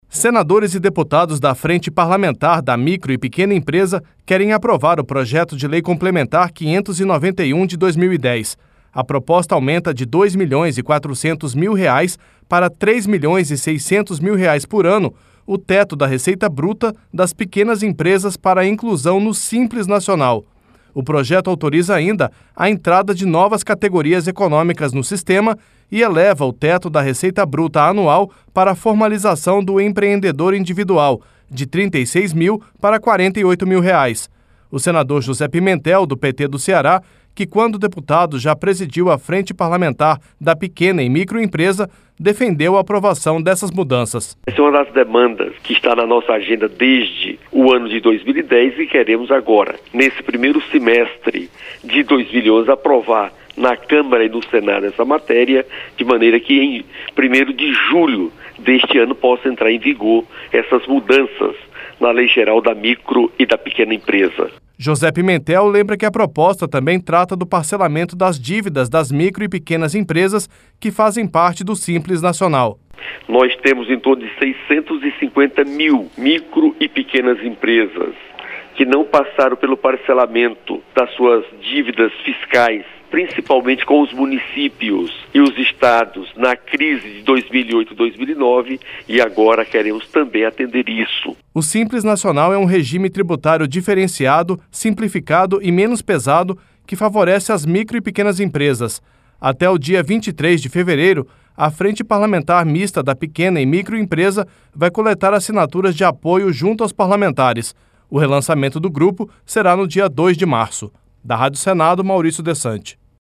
O senador José Pimentel, do PT do Ceará, que, quando deputado, já presidiu a frente parlamentar da pequena e micro empresa, defendeu a aprovação dessas mudanças